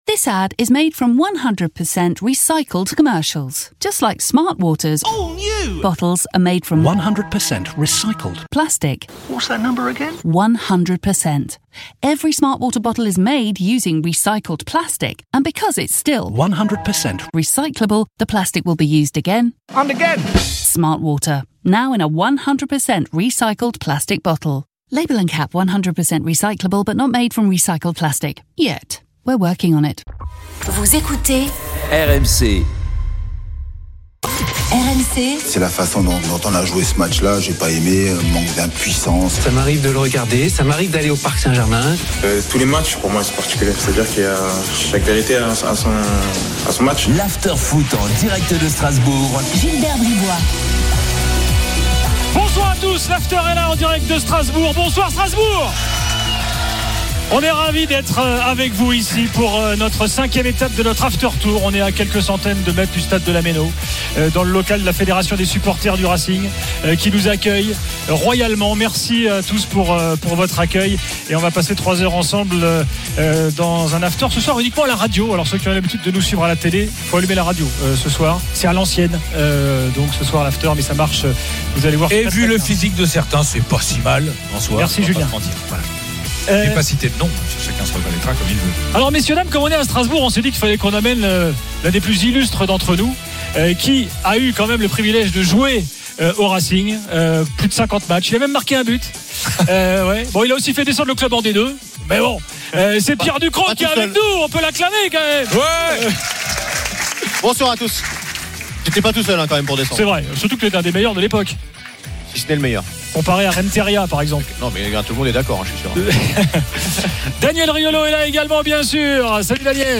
L'Afterfoot du 02 mars : En direct de Strasbourg – 21h/22h